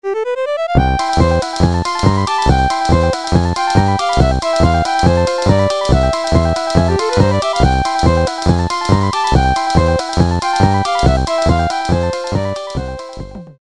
Nokia полифония. Мультики